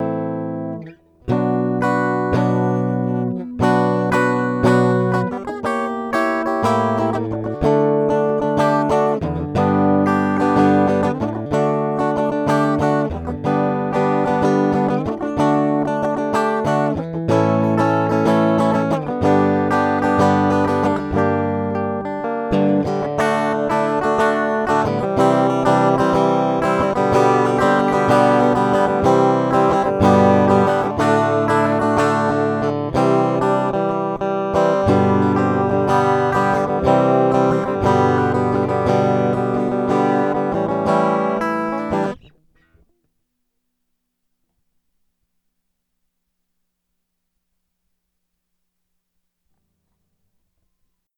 noodling on his guitar
This is a plain signal pathway into Audacity on that laptop to the right.
It’s an AKG C 414B-XLII
guitar recording